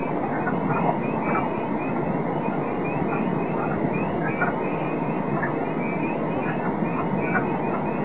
Wood Frog
Voice- a series of hoarse, clacking duck-like quacks.
small chorus (174 Kb)
WoodFrog.wav